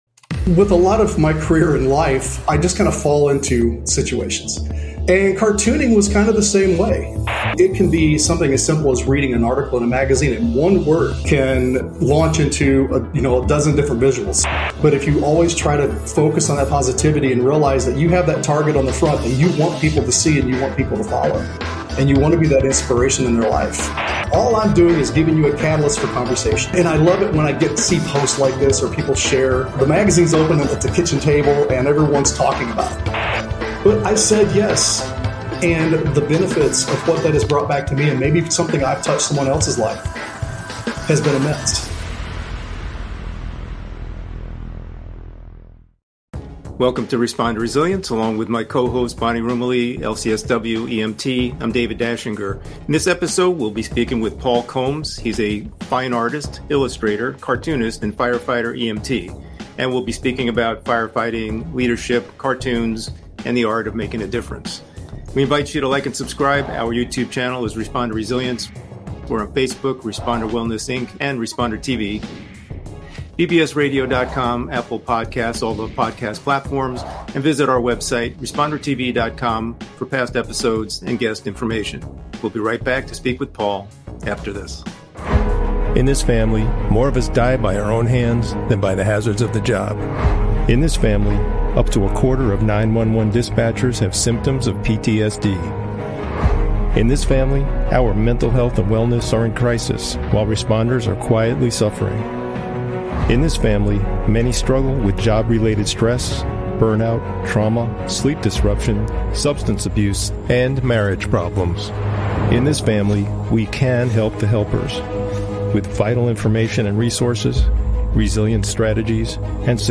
🎧 Get ready for an incredible conversation on Responder Resilience!